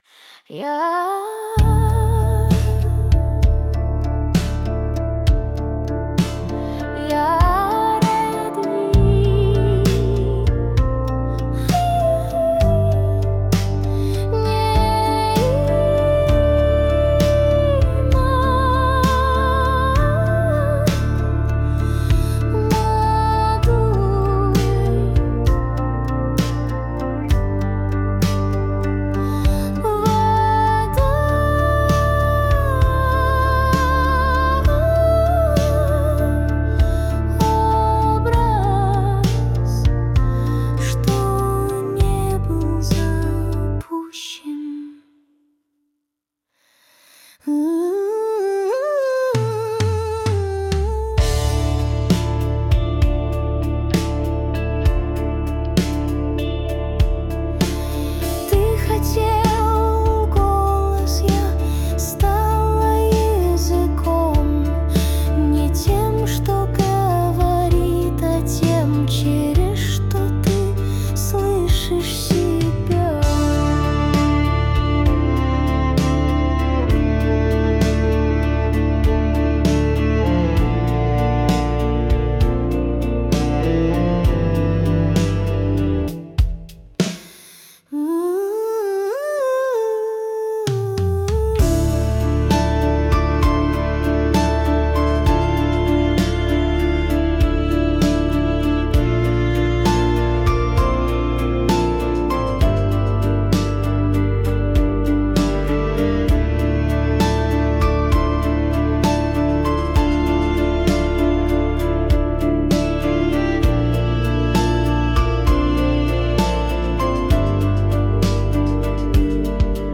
Речь - как глитч.